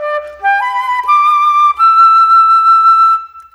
Rock-Pop 01 Flute 05.wav